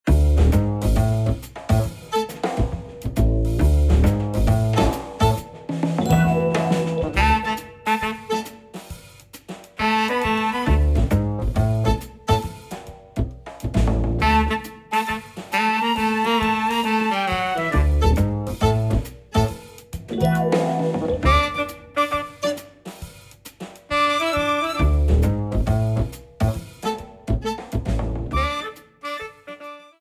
Shortened, applied fade-out and converted to oga